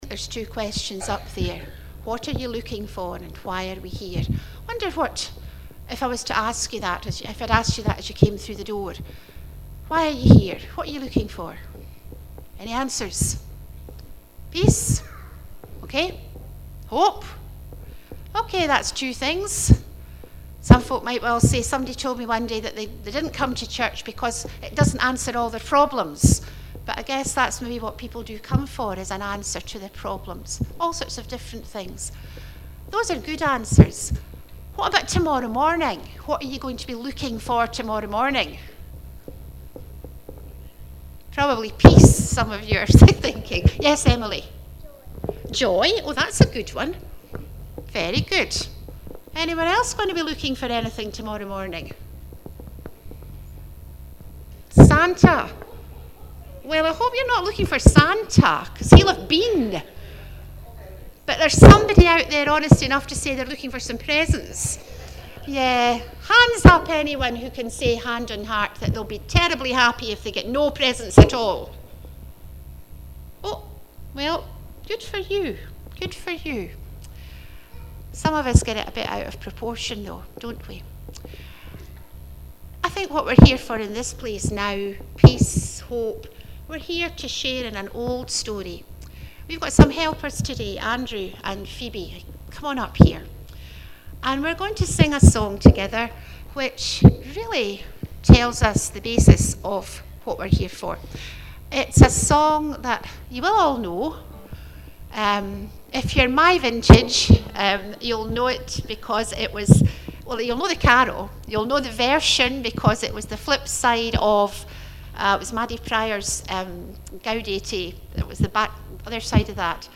Fourth Advent Afternoon Family Service